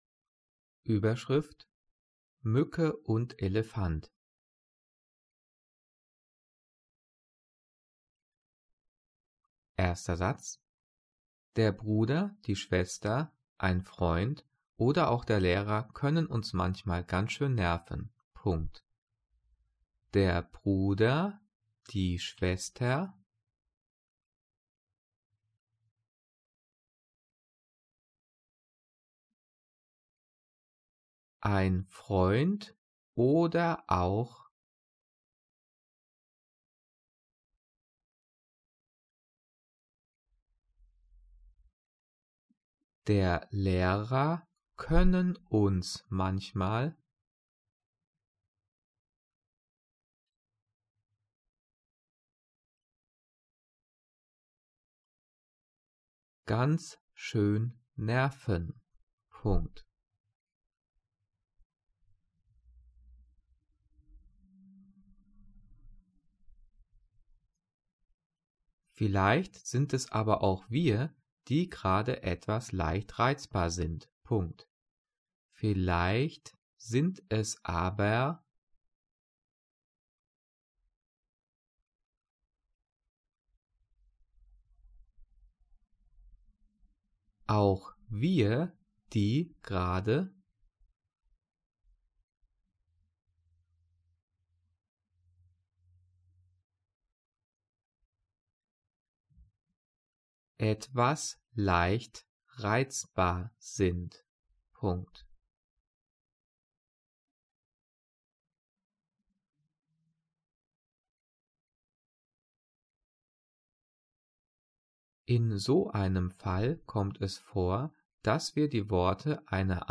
Übungsdiktat 'Mücke und Elefant' für die 5. und 6. Klasse zum Thema Zeichensetzung diktiert und mit Lösung.